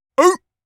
seal_walrus_bark_single_01.wav